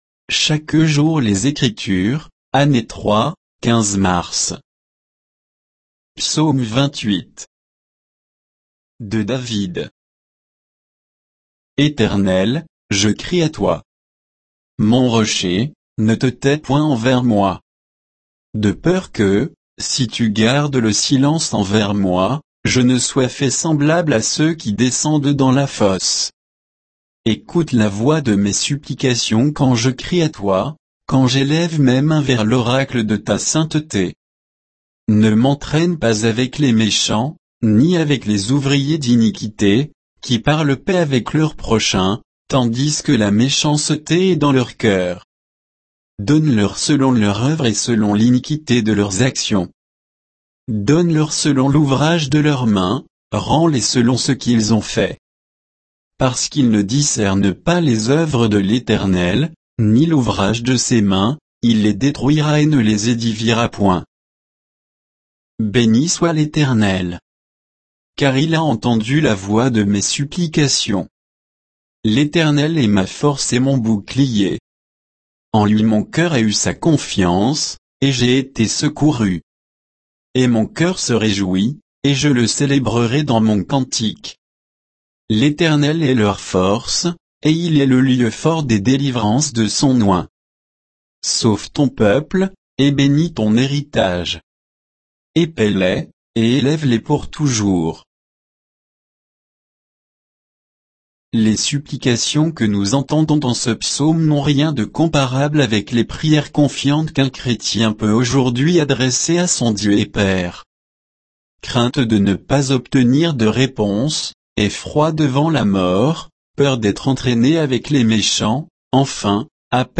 Méditation quoditienne de Chaque jour les Écritures sur Psaume 28